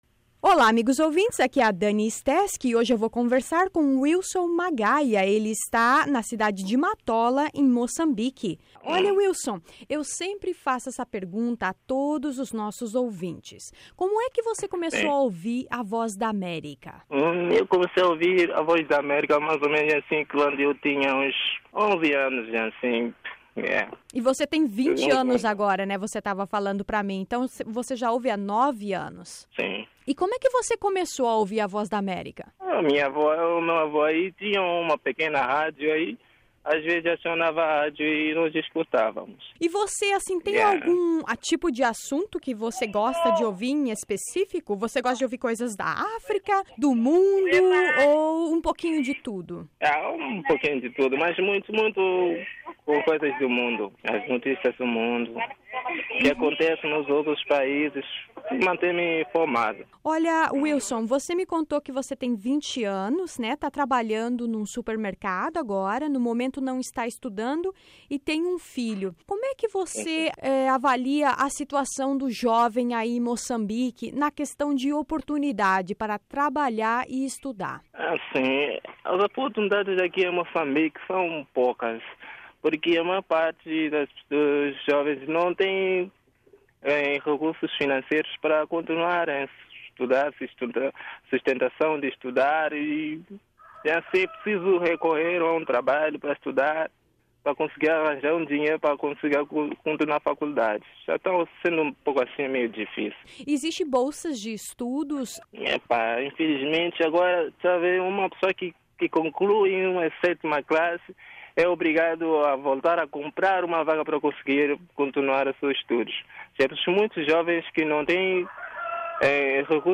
Conversa semanal com os ouvintes da Voz da América.